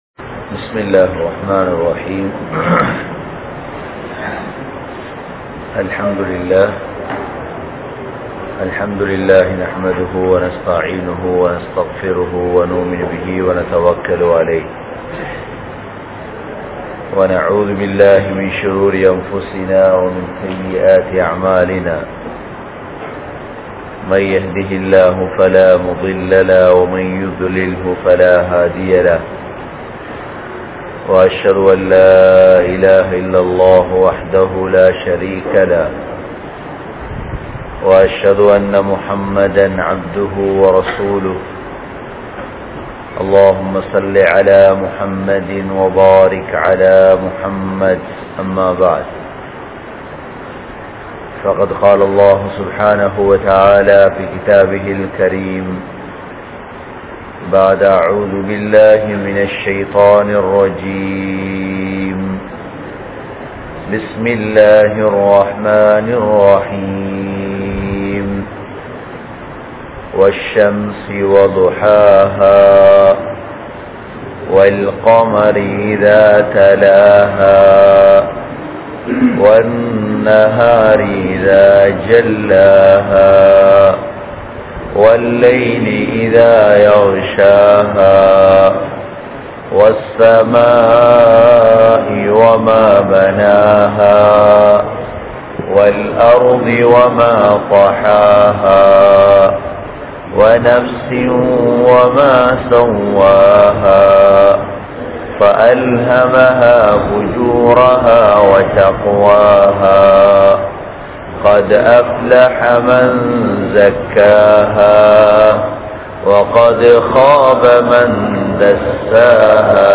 Audio Bayans
Meera Masjith(Therupalli)